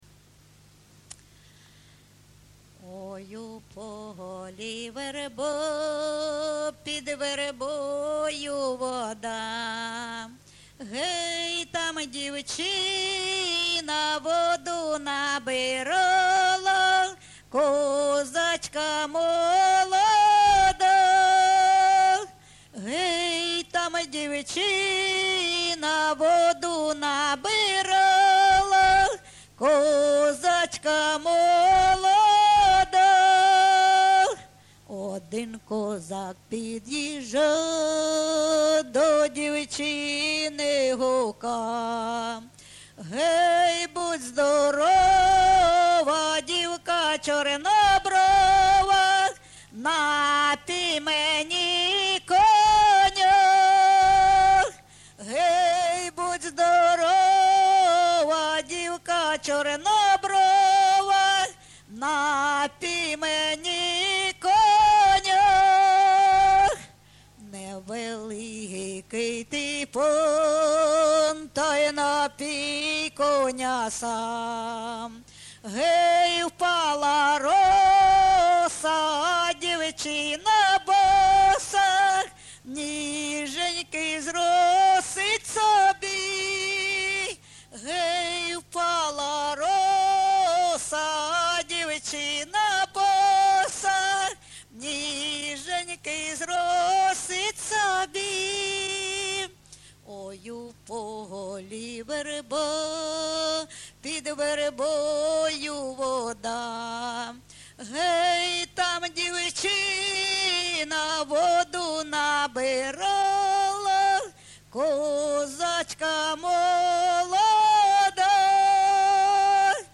ЖанрКозацькі
Місце записус-ще Новодонецьке, Краматорський район, Донецька обл., Україна, Слобожанщина